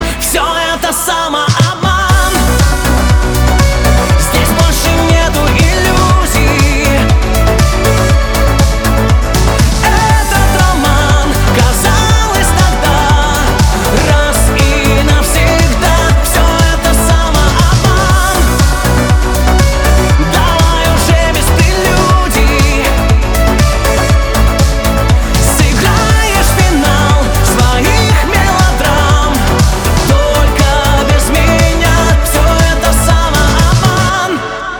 Энергичные
Женский голос
Танцевальные